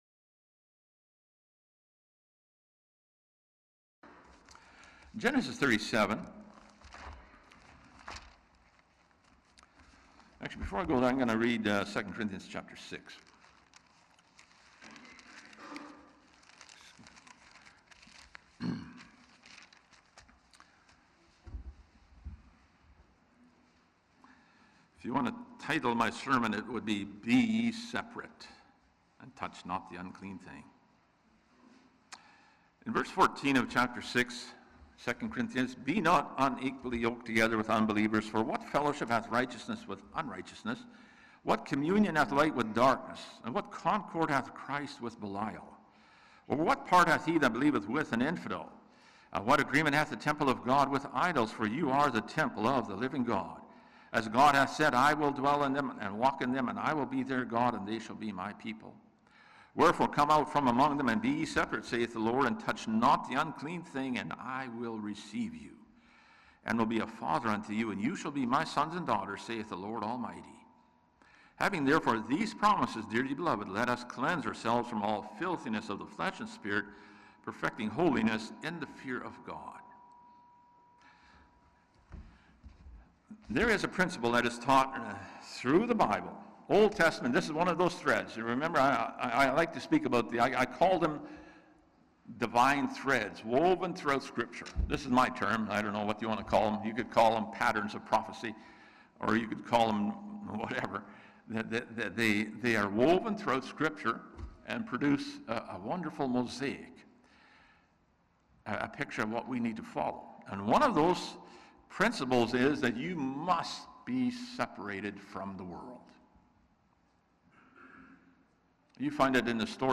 Note: The first part of the sermon is missing due to a recording problem.
Service Type: Sunday Morning